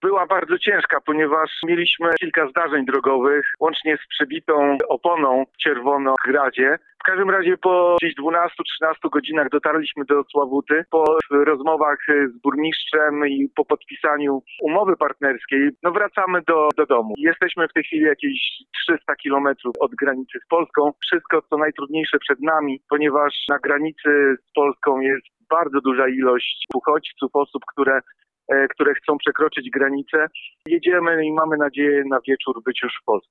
– Mówi burmistrz miasta.